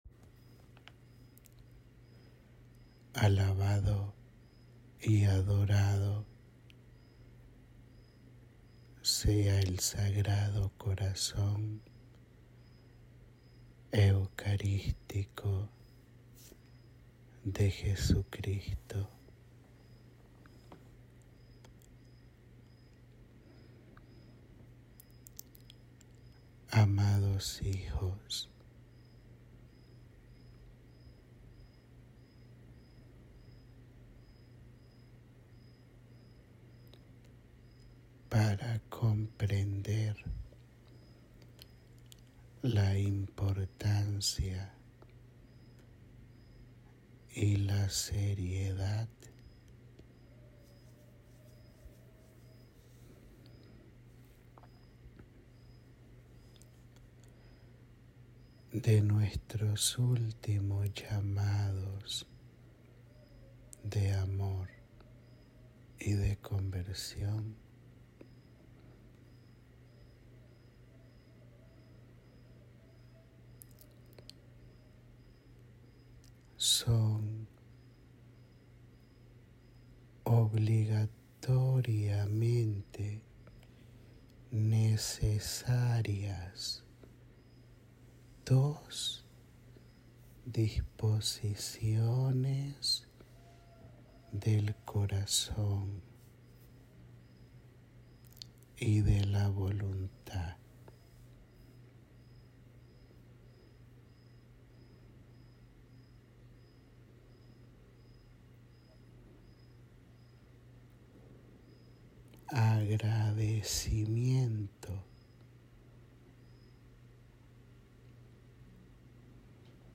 Áudio da Mensagem